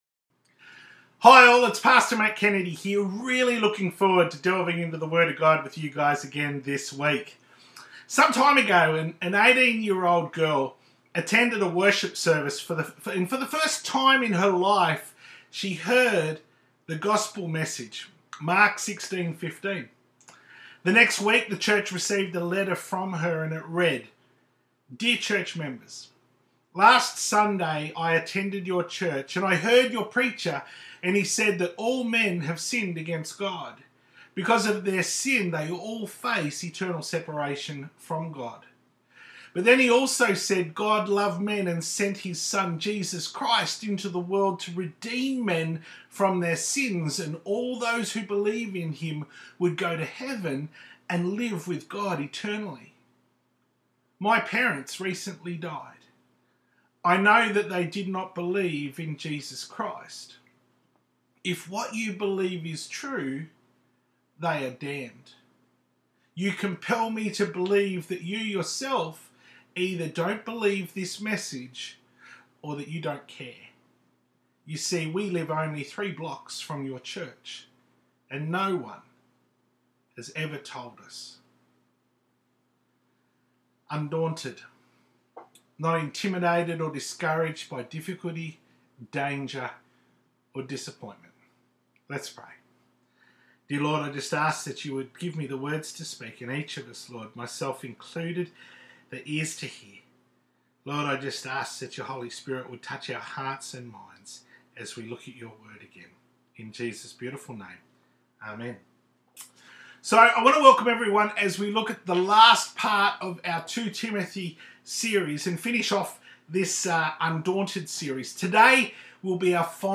Sermon_ Undaunted ‘Part 10’